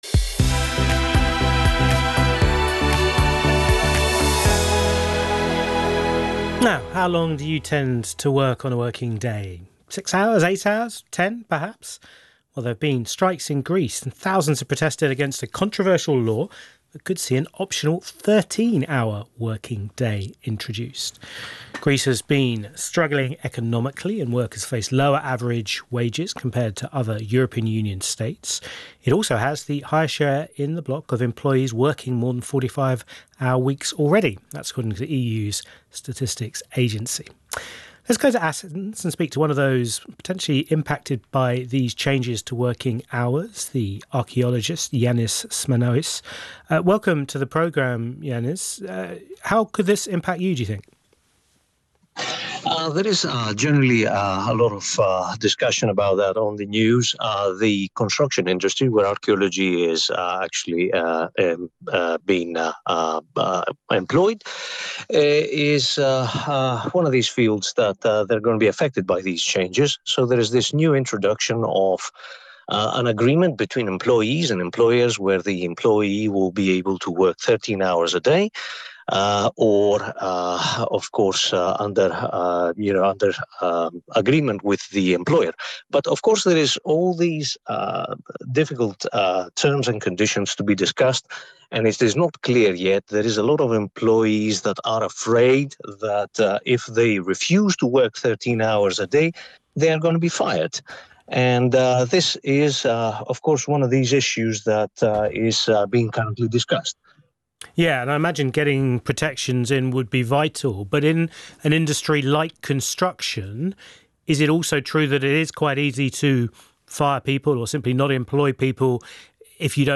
Live broadcastings:
Interview on BBC radio in relation to the new labour law allowing 13-hour working days on 15/10/2025: